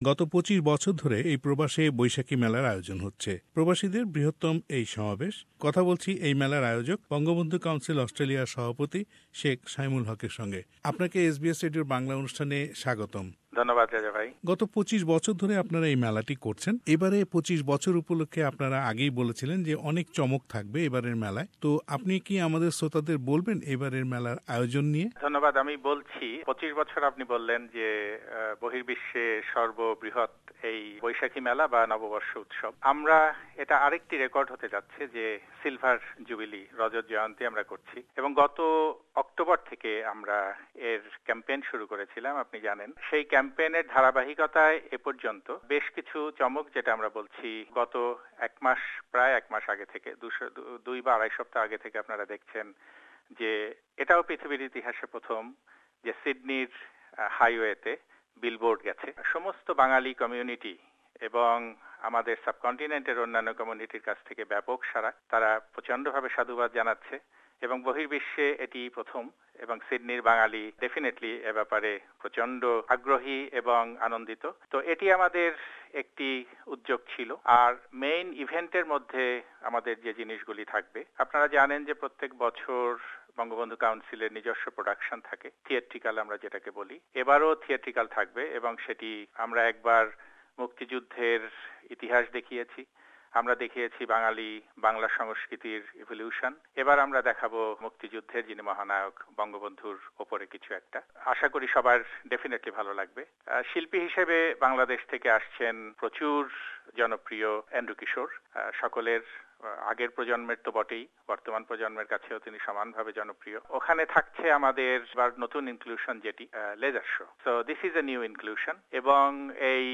Bangla New Year Festival : Interview